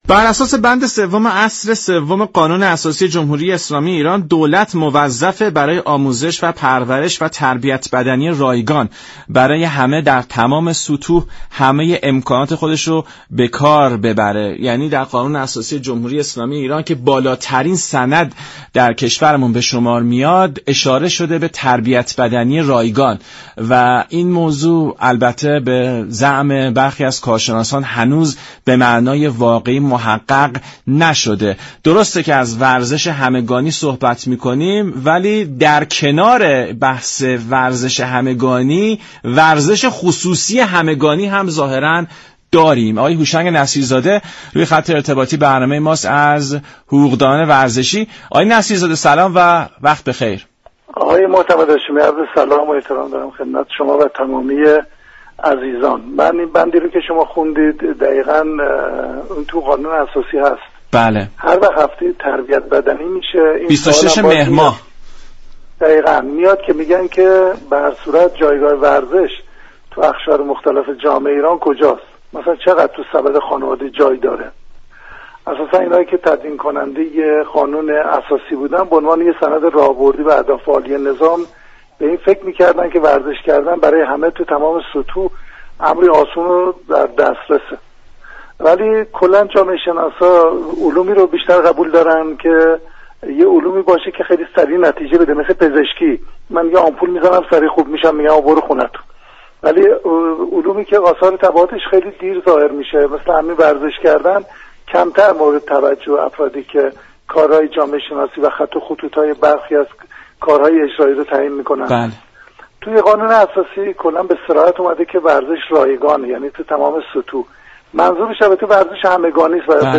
در این گفت و گوی رادیویی